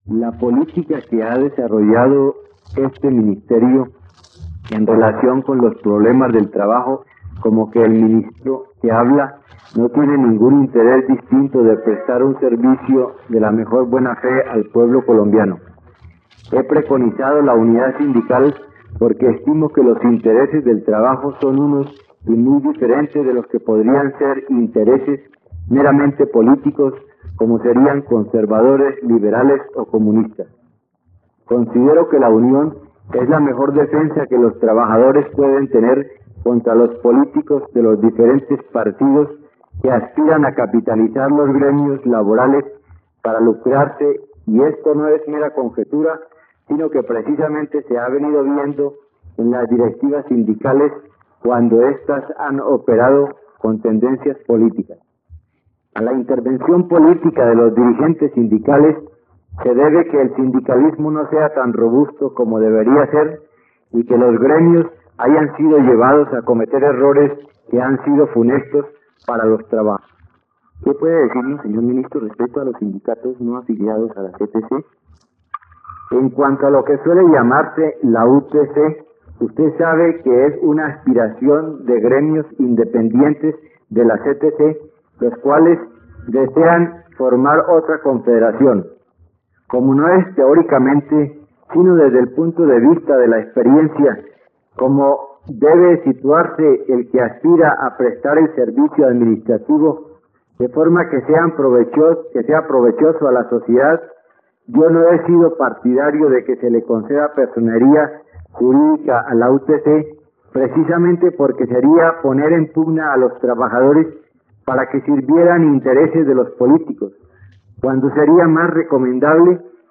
[Entrevista al ministro de trabajo].